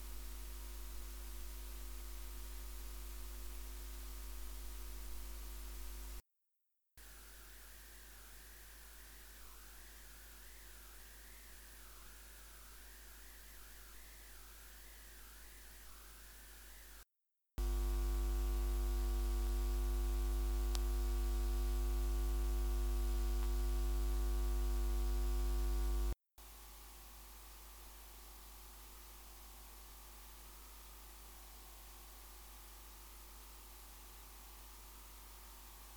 Line in/Mic in Rauschen/Brummen
Wenn ich mein preamp per Kabel (Xlr zu 3,5mm Klinke) an mein mainboard schließe hört man ein störendes rauschen. Dabei ist es egal ob ich meinen preamp ein oder ausgeschaltet habe oder ich ein anderes Kabel verwende.